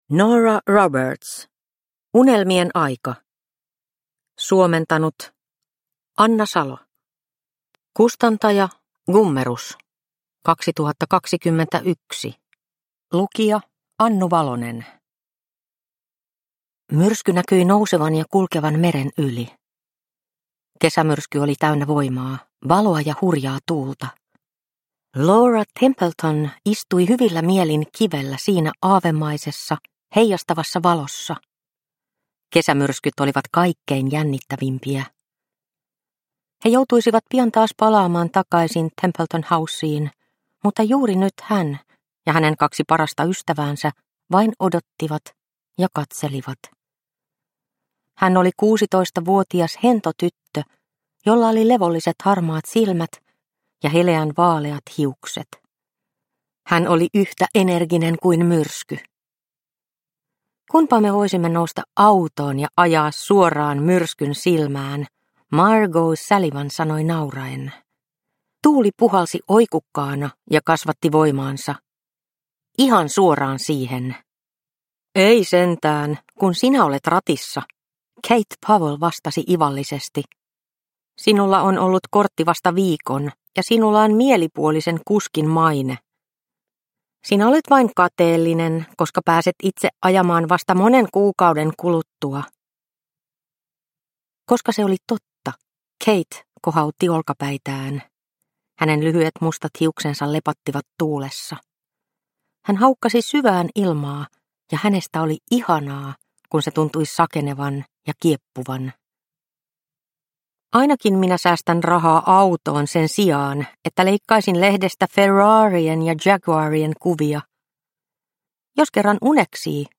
Unelmien aika – Ljudbok – Laddas ner